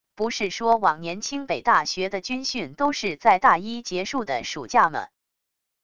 不是说往年清北大学的军训都是在大一结束的暑假么wav音频生成系统WAV Audio Player